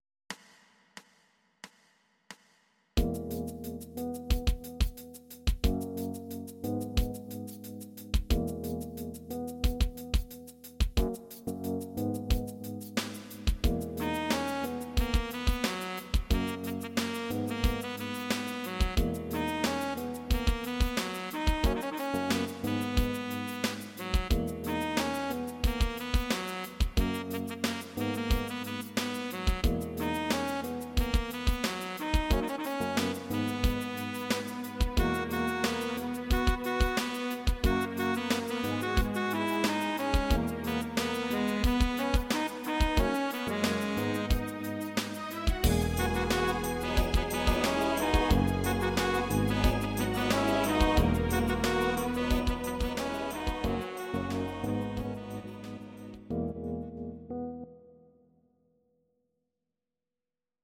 Audio Recordings based on Midi-files
Pop, 2000s